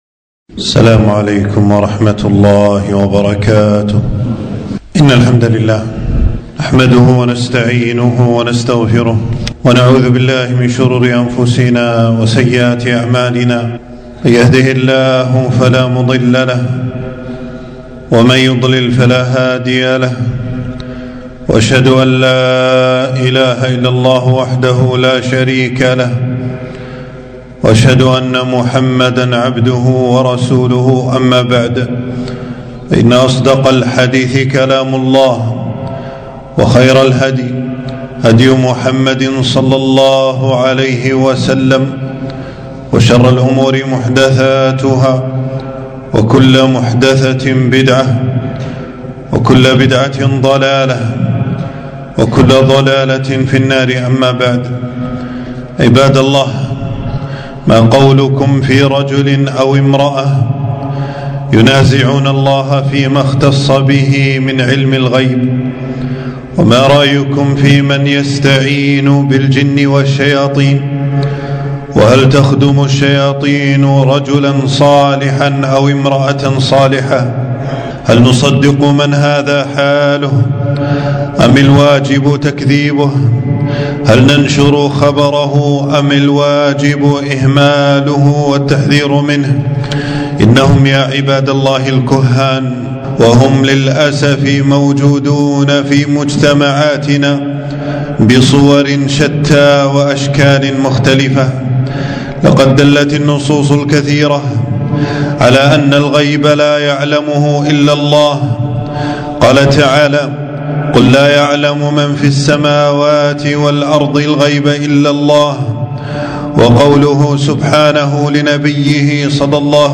خطبة - التحذير من الكهان وبيان خطر تصديقهم